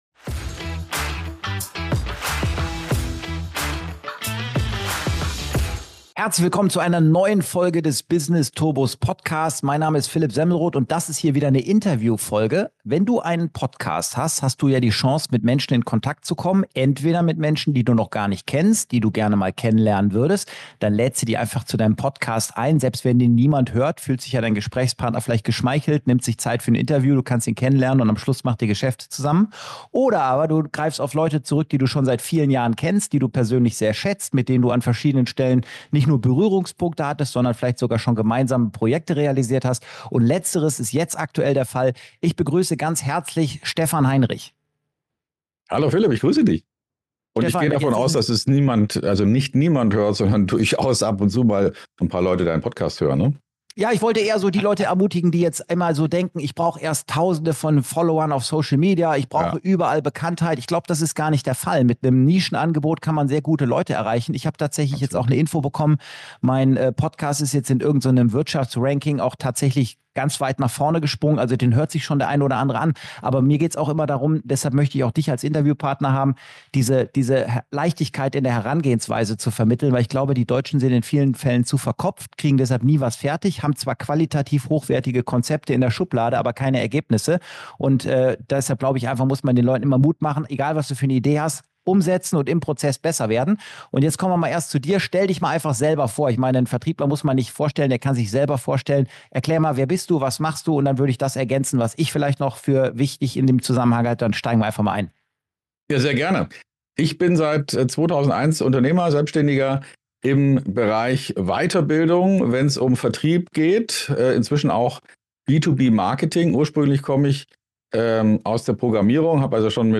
#041 INTERVIEW